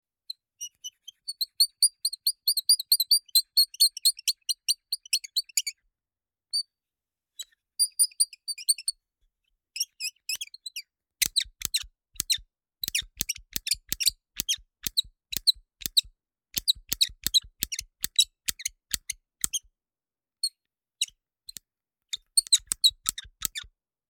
Звуки плоскогубцев
Звук металлических плоскогубцев в руке